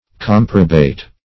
Search Result for " comprobate" : The Collaborative International Dictionary of English v.0.48: Comprobate \Com"pro*bate\, v. i. [L. comprobatus, p. p. of comprobare, to approve wholly.] To agree; to concur.
comprobate.mp3